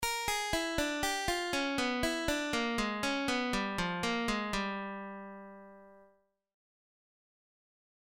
Blues lick > Lick 2